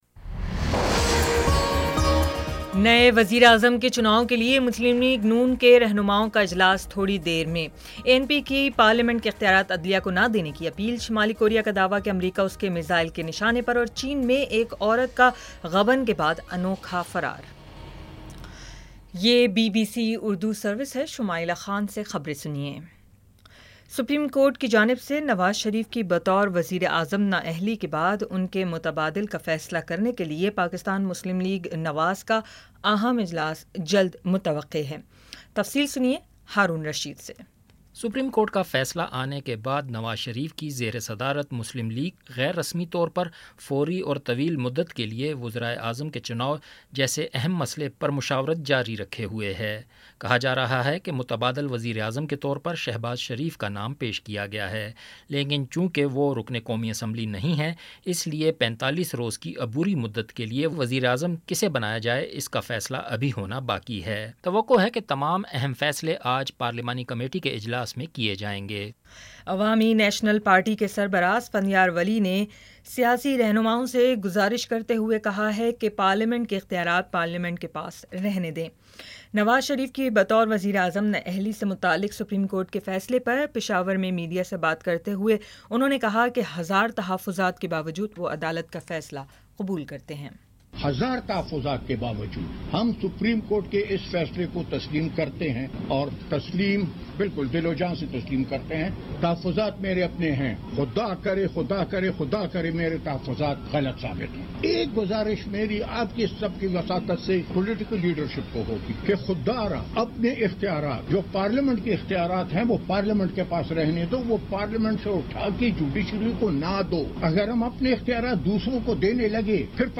جولائی 29 : شام پانچ بجے کا نیوز بُلیٹن